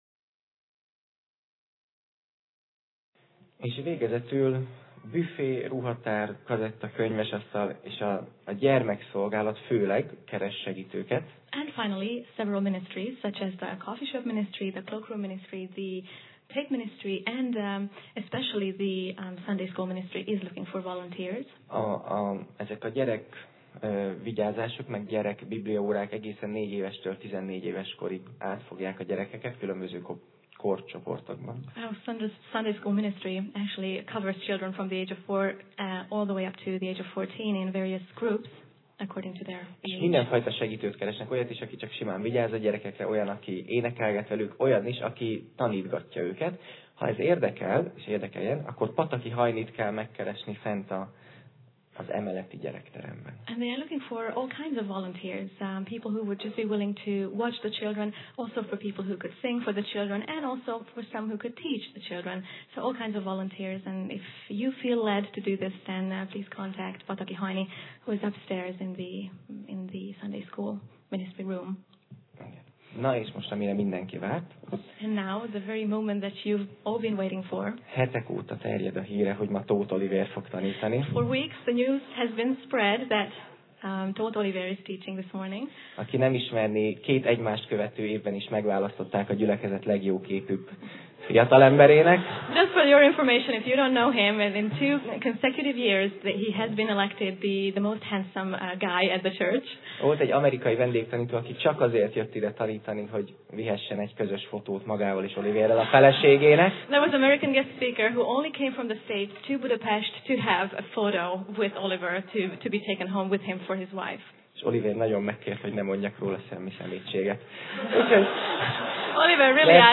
Sorozat: Tematikus tanítás
Alkalom: Vasárnap Reggel